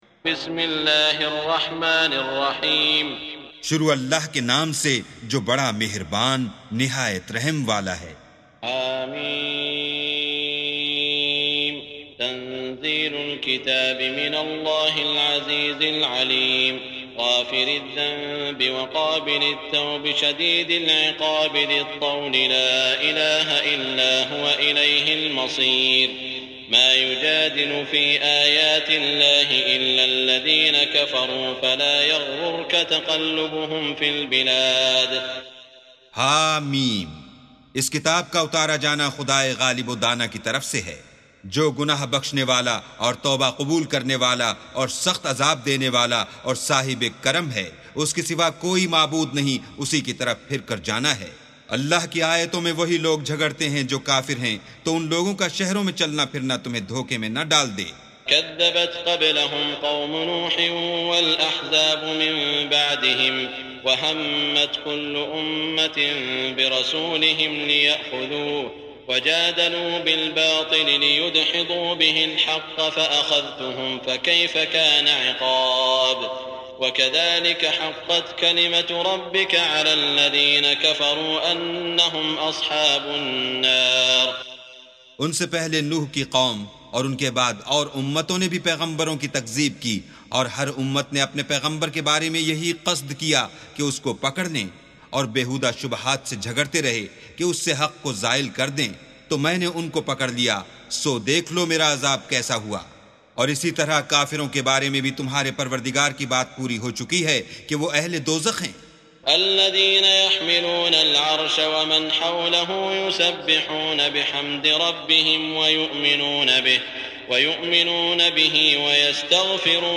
سُورَةُ غَافِرٍ بصوت الشيخ السديس والشريم مترجم إلى الاردو